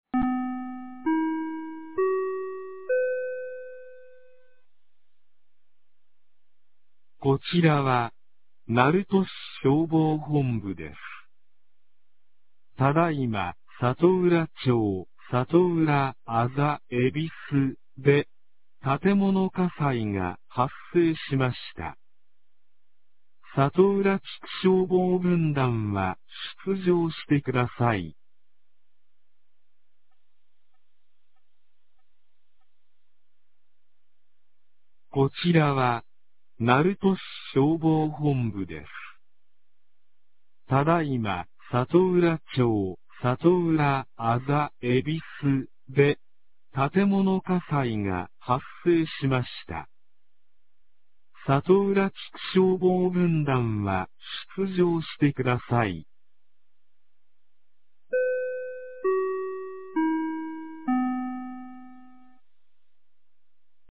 2025年02月23日 21時27分に、鳴門市より瀬戸町へ放送がありました。